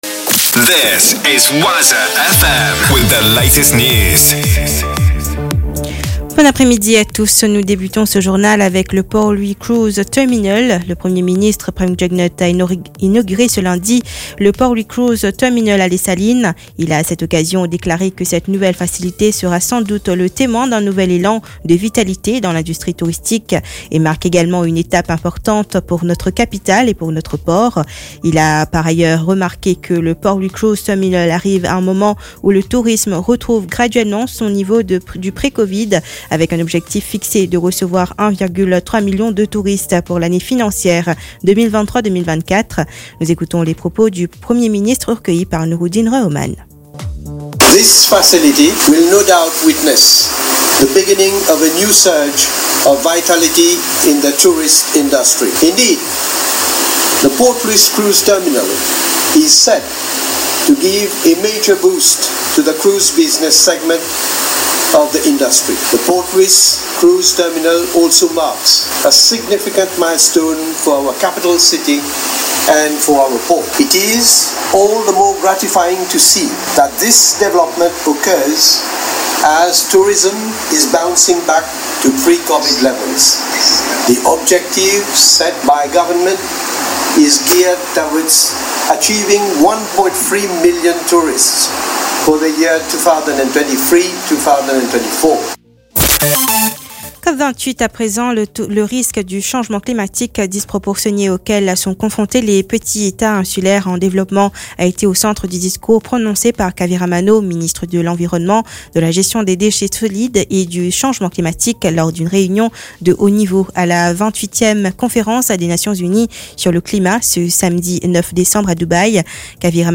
NEWS 16H - 11.12.23